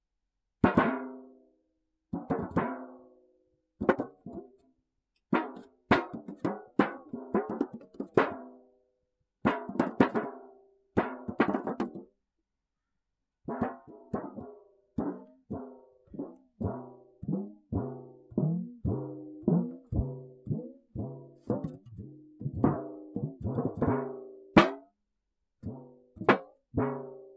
• metallic cracker box hits.wav
Experience the unique, resonant sound of a metallic cracker box being hit.
metallic_cracker_box_hits_4yz.wav